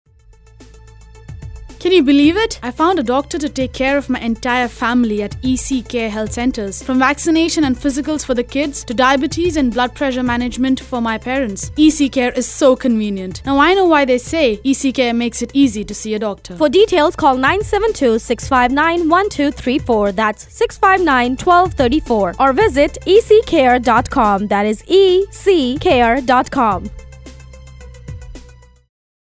Radio Spot on Salaam Namaste FM 104.9